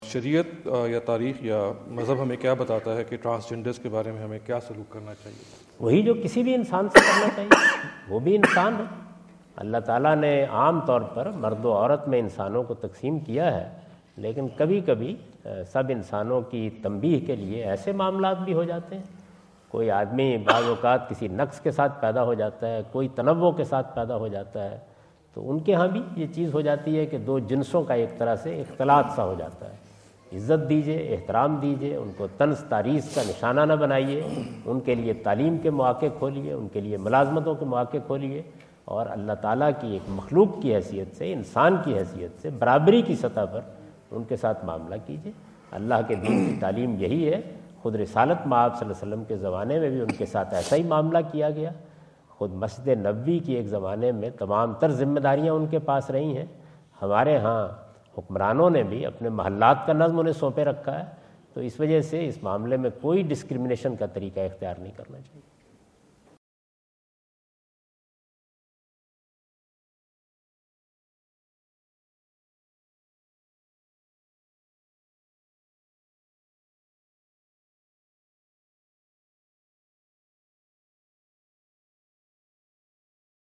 Category: English Subtitled / Questions_Answers /
Javed Ahmad Ghamidi answer the question about "Treating Transgenders" asked at The University of Houston, Houston Texas on November 05,2017.
جاوید احمد غامدی اپنے دورہ امریکہ 2017 کے دوران ہیوسٹن ٹیکساس میں "خواجہ سراوں کے ساتھ برتاؤ" سے متعلق ایک سوال کا جواب دے رہے ہیں۔